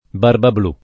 barbabl2+] (meno bene Barbablu [id.]) soprann. m. — il protagonista (fr. Barbebleue) d’una fiaba di Ch.